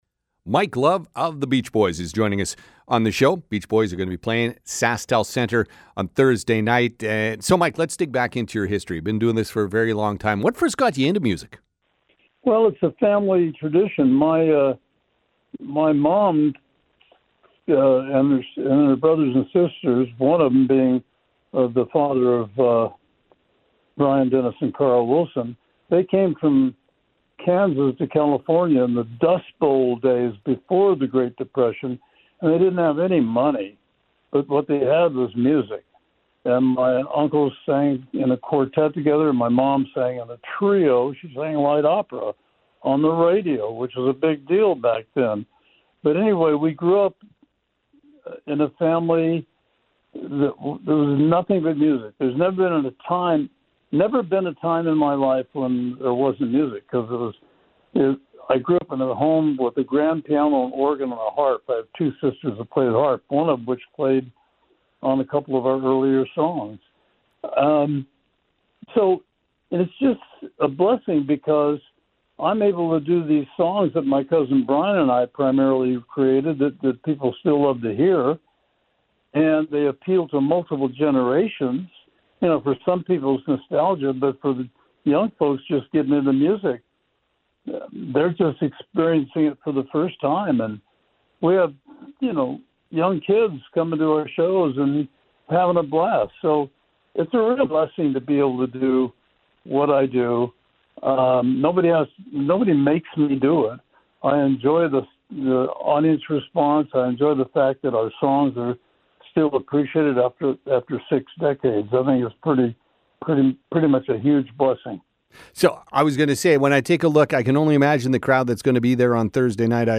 mike-love-interview.mp3